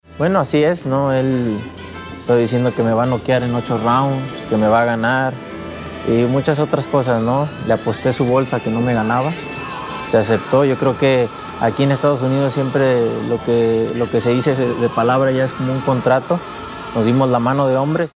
En algo que por primera vez se hace entre os boxeadores públicamente, Saúl el Canelo Alvarez y Julio César Chávez Junior decidieron apostar sus bolsas de dinero de la pelea. Canelo cuenta como está el asunto…